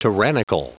Prononciation du mot : tyrannical